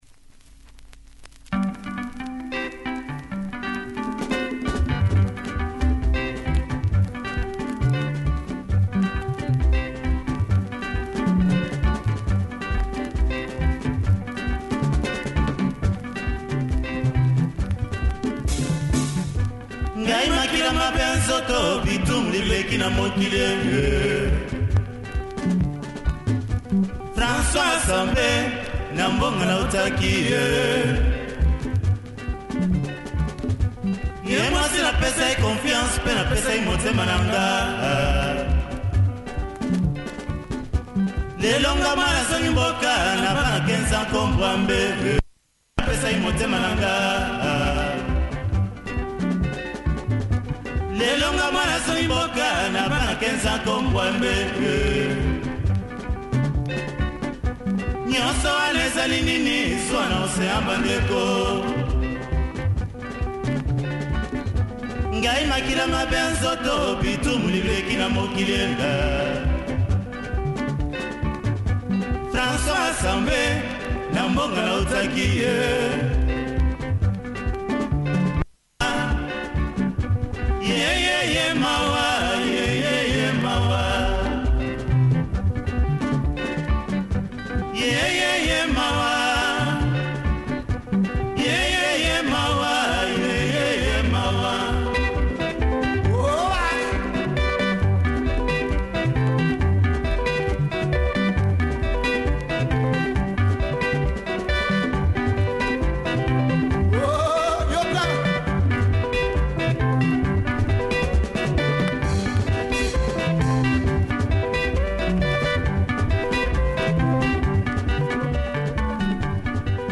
Great Lingala track here, super production